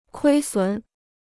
亏损 (kuī sǔn): deficit; (financial) loss.